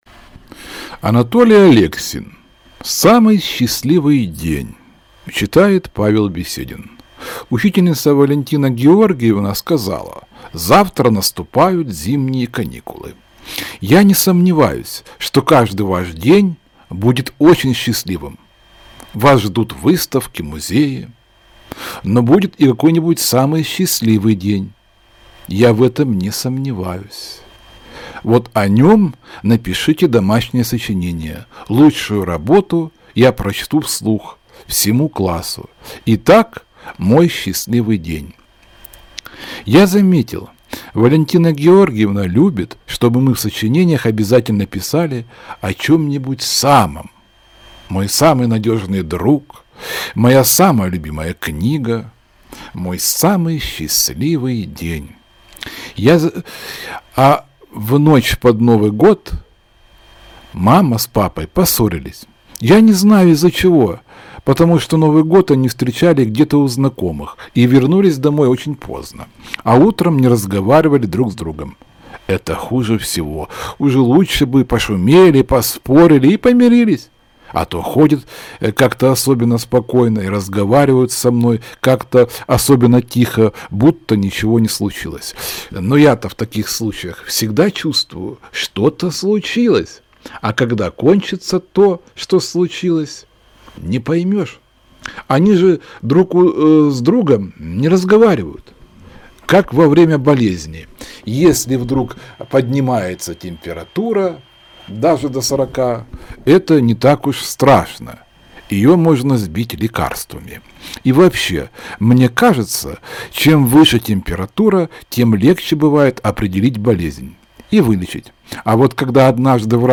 Самый счастливый день - аудио рассказ Алексина - слушать онлайн